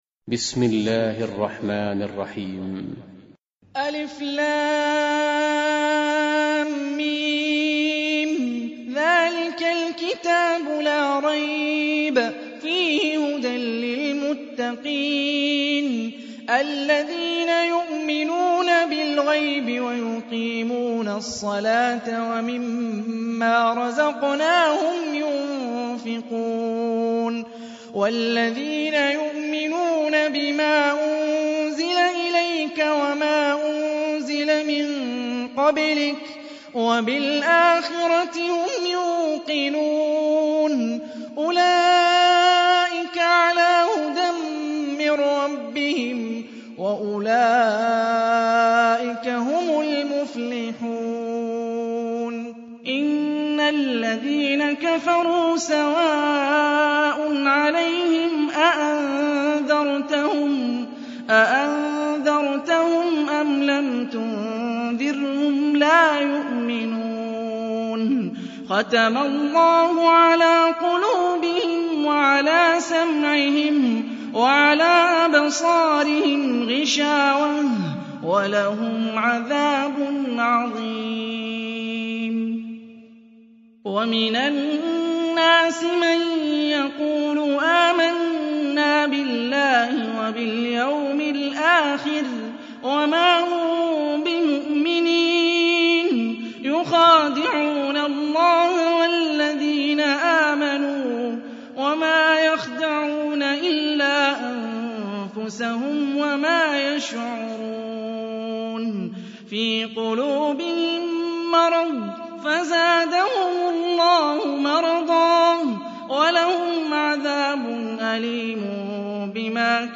002 Baqara I Бақара - Haniy ar-Rifaiy
Қуръони карим тиловати, Қорилар.